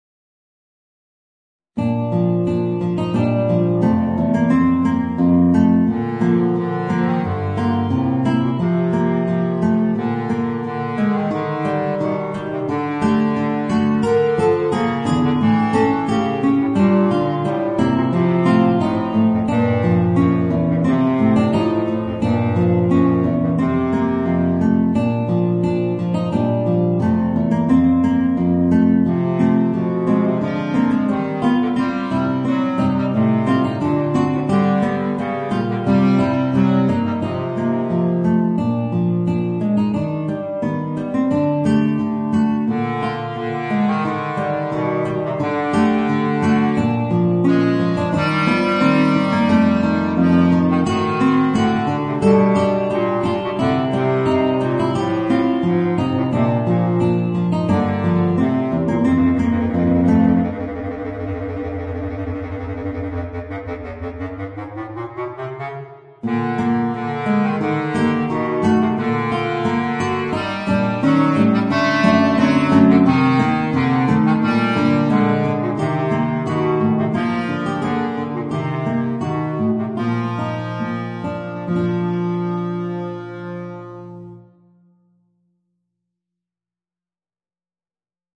Voicing: Guitar and Bass Clarinet